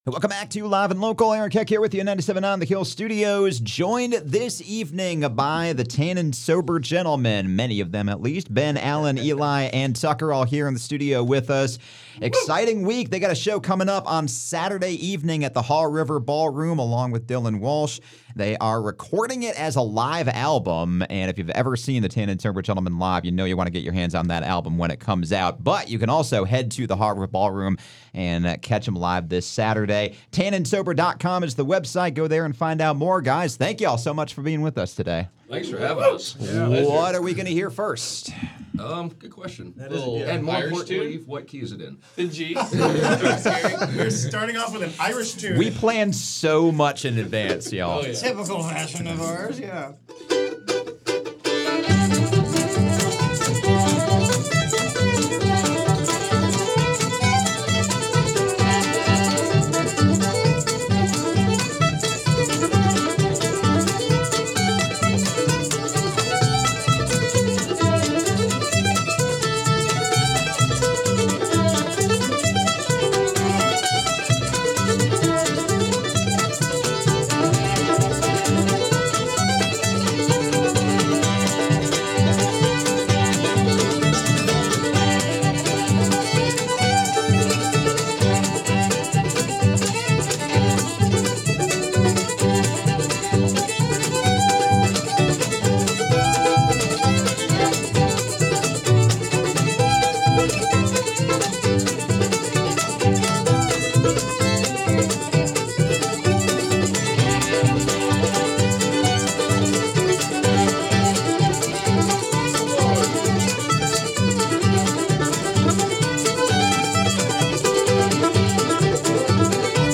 Bringing the fire with a wild blend of Celtic and punk music
play three songs live